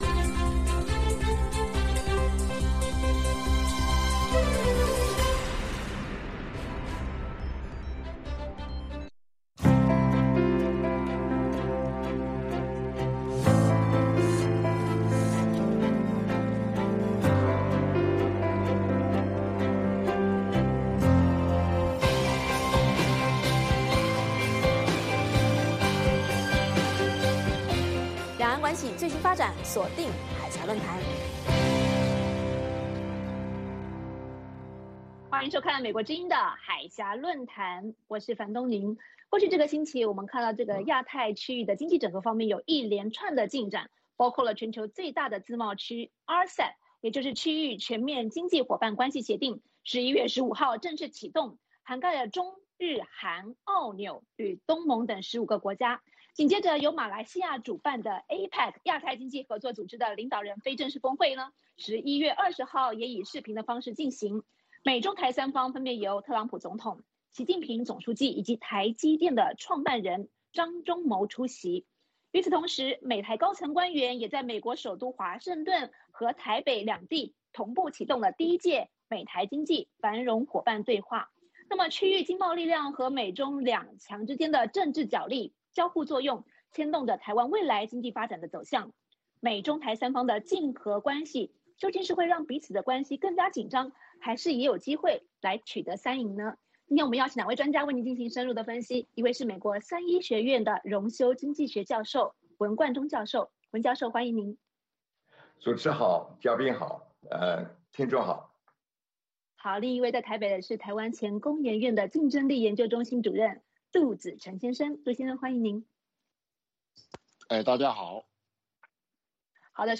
美国之音中文广播于北京时间每周日晚上9-10点播出《海峡论谈》节目(电视、广播同步播出)。《海峡论谈》节目邀请华盛顿和台北专家学者现场讨论政治、经济等各种两岸最新热门话题。